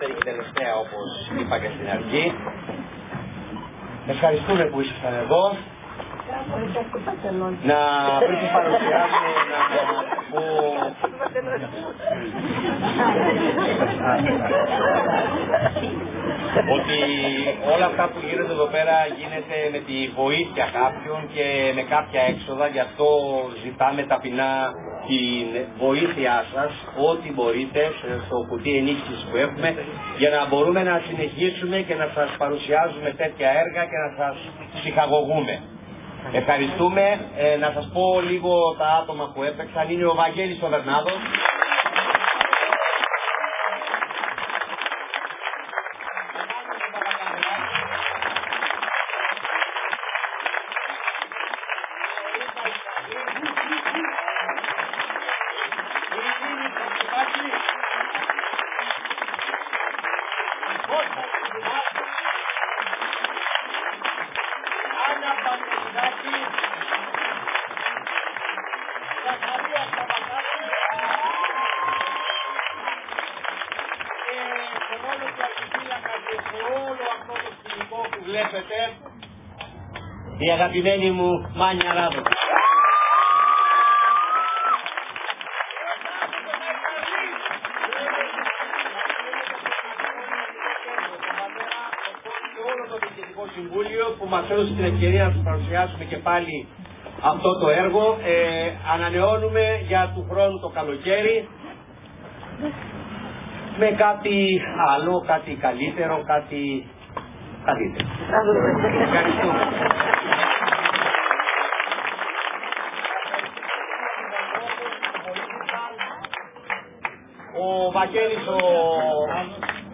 Η ερασιτεχνική θεατρική ομάδα του Πνευματικού-Νεανικού Κέντρου
Η παράσταση ανέβηκε στο Κηποθέατρο της “Πύλης Βηθλεέμ” των Ενετικών Τειχών στον Δήμο Ηρακλείου στις 2 και 3 Αυγούστου 2025.